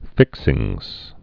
(fĭksĭngz)